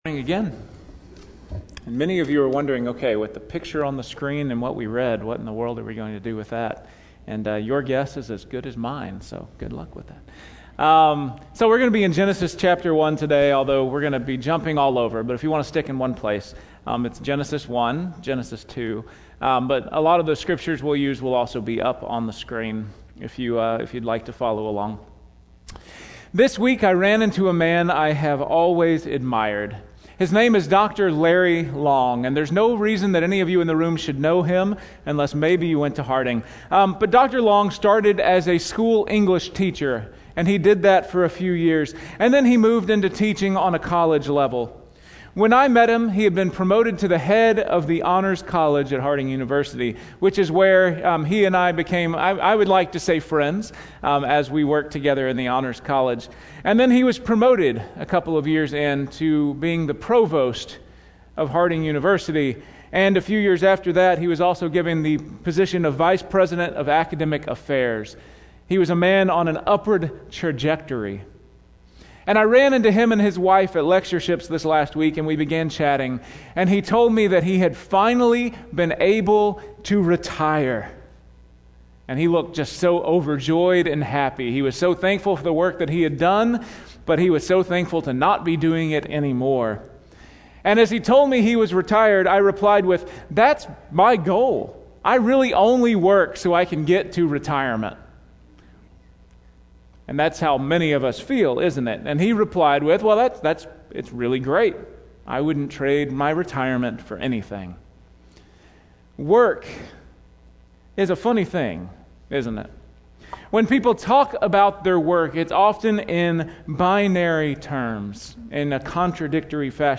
Weekly Sermon Audio “God at Work”